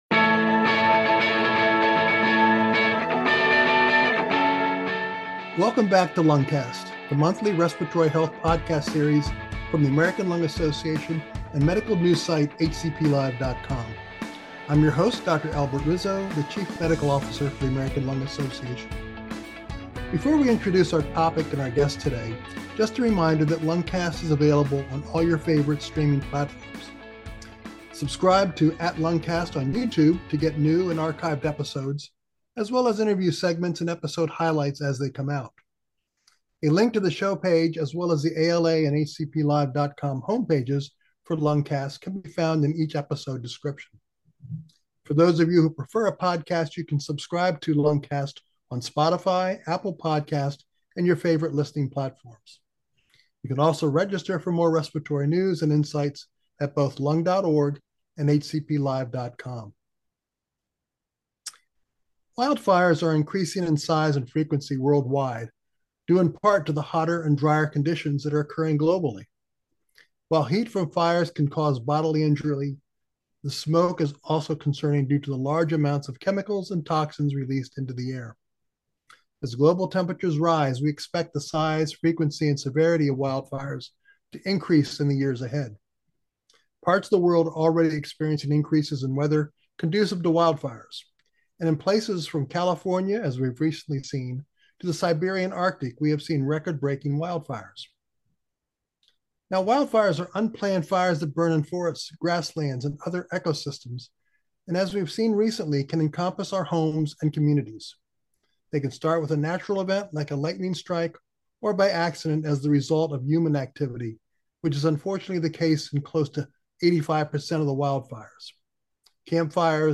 The enlightening conversation also covers the massive cleanup efforts from the recent Los Angeles wildfires and how related exposures may pose health risks.